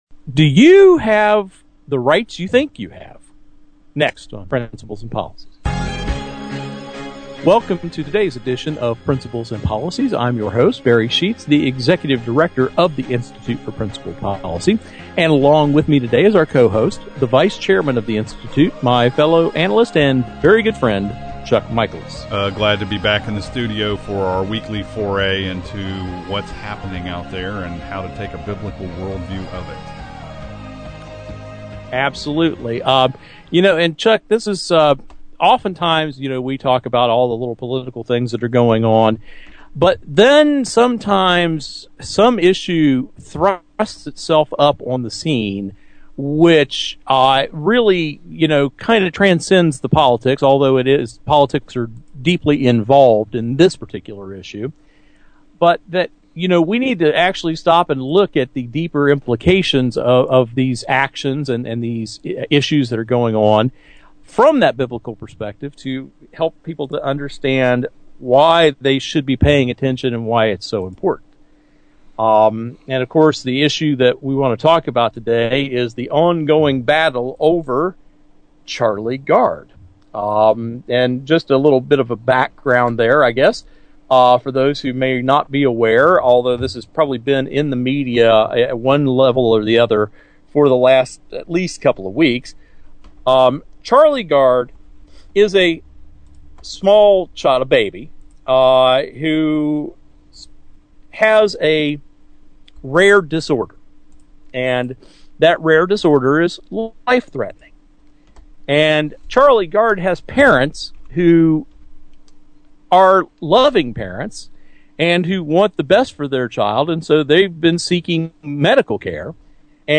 Our Principles and Policies radio show for Saturday July 25, 2015.